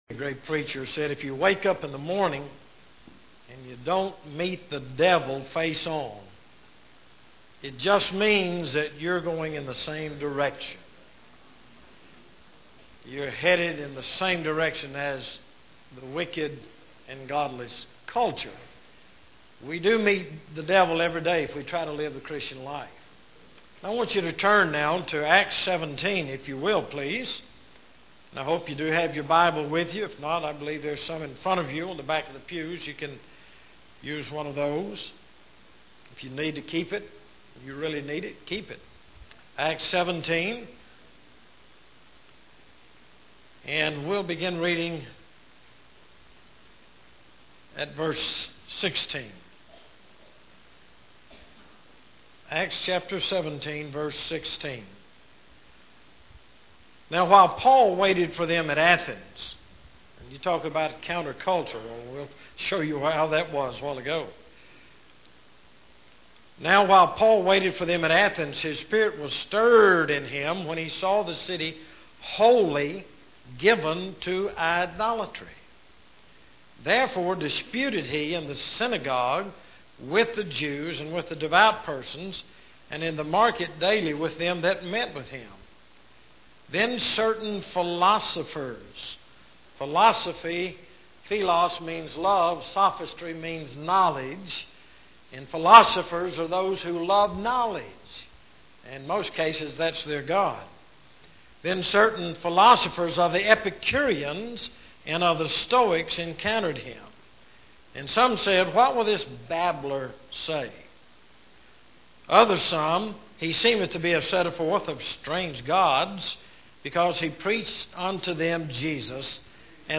Paul's Sermon on Mars' Hill